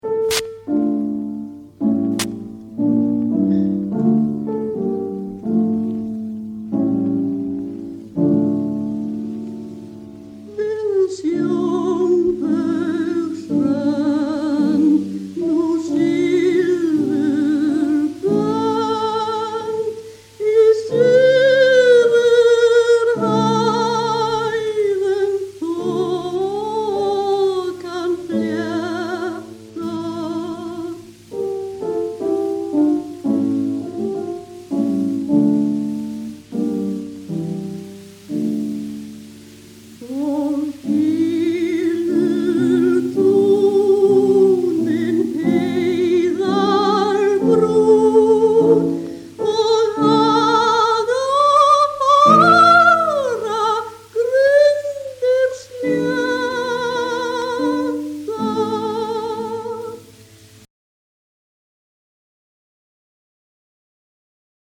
syngur í tóndæminu